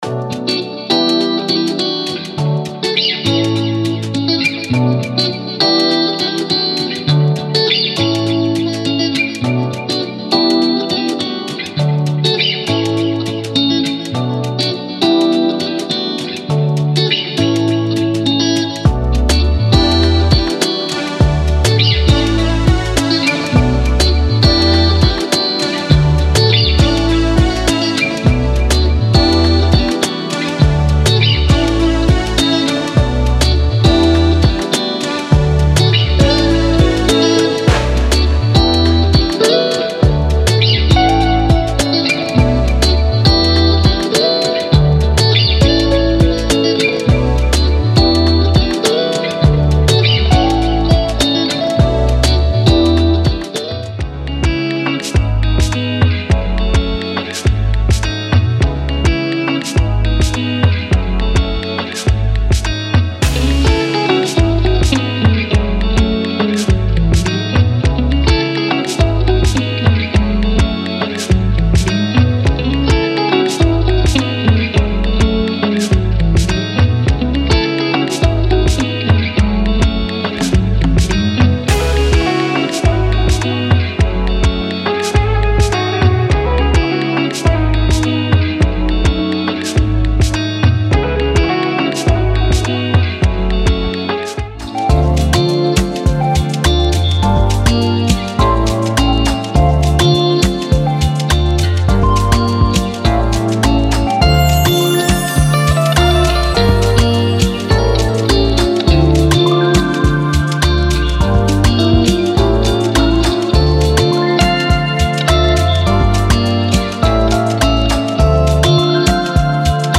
ダウンテンポ系では特に使い勝手も良く、
Genre:Downtempo
ここは、穏やかなソウルとトロピカルなリズムが出会う場所です。
90 Drums Loops
10 Synth Bass
37 Guitar Parts
13 Electric Piano
22 Synth Parts
2 Flute